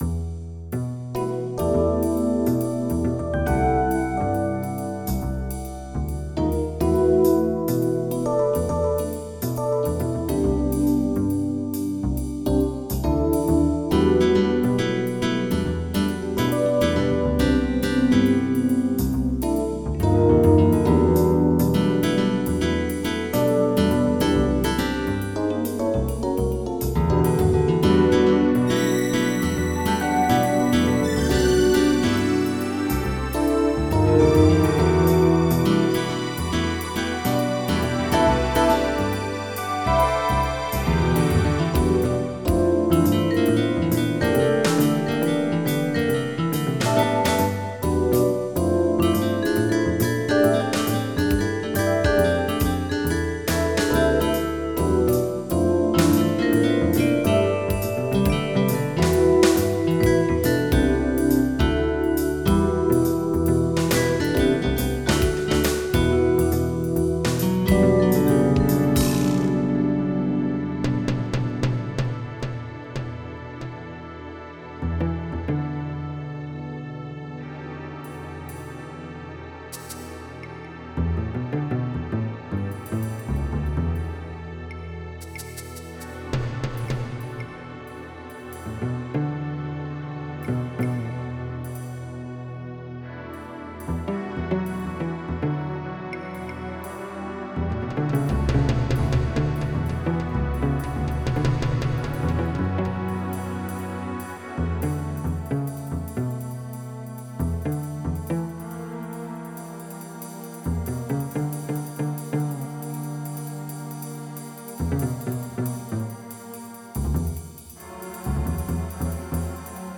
Jazz
General MIDI